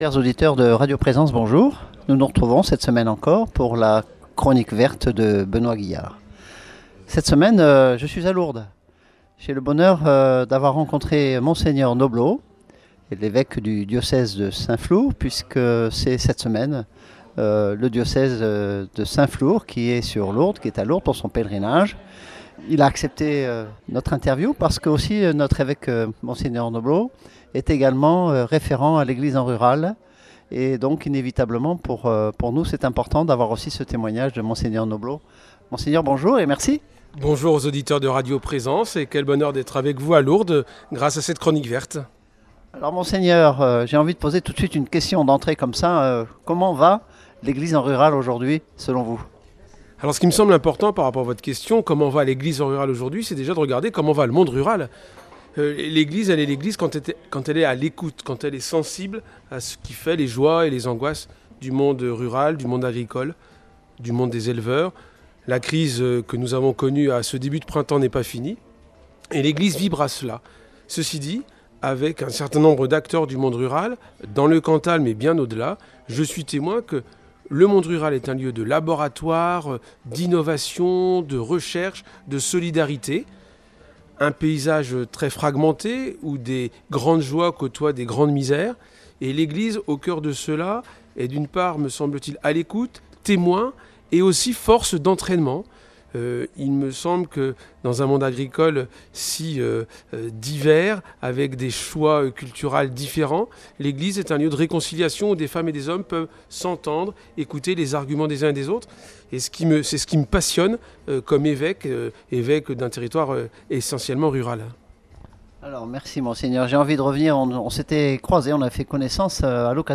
Mgr Noblot, évêque du diocèse de St Flour et référent national de Mission de l’Eglise en Rural. Il évoque la situation du rural en France et de l’Eglise en rural, l’évènement en 2022 de Terres d’espérance.